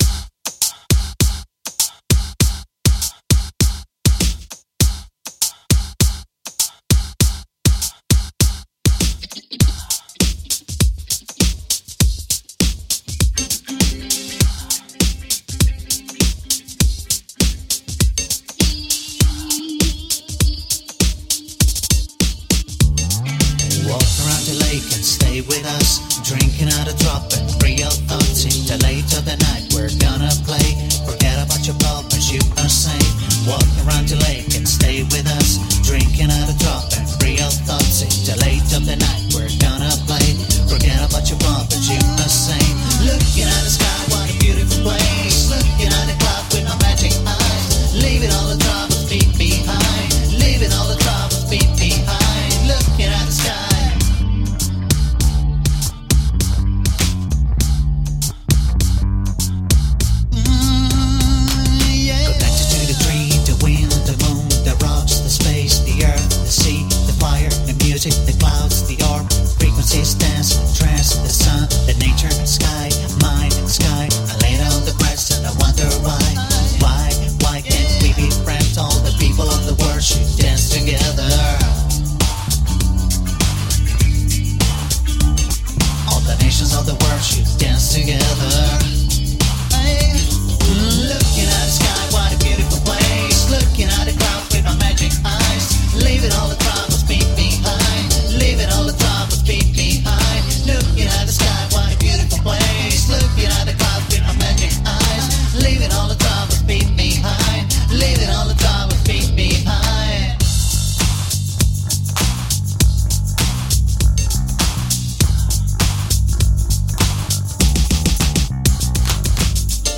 Vocals & Lyrics, Backing vocals, Keyboards
Bass, Drum programming, Synths
Lead & Rhythm Guitar
Tagged as: Electro Rock, Alt Rock, Electric Guitar